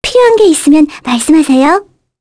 Mirianne-vox-dia_02_kr.wav